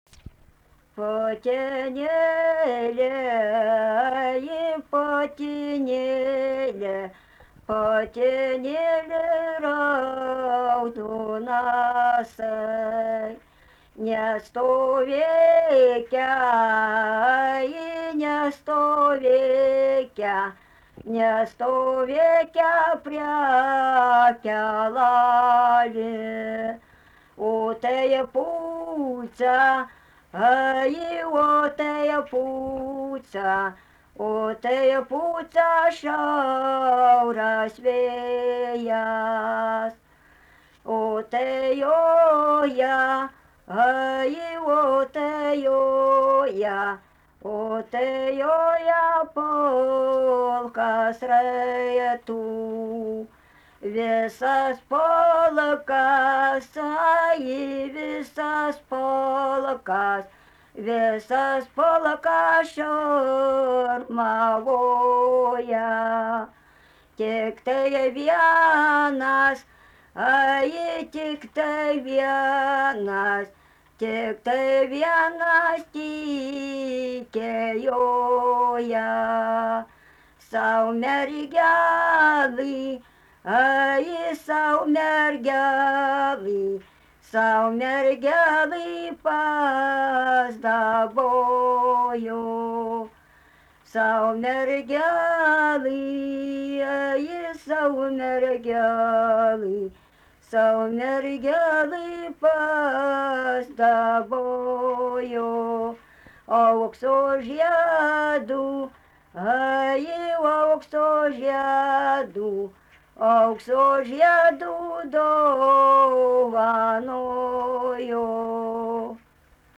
daina, vestuvių
Atlikimo pubūdis vokalinis
Pastabos 2-3 balsai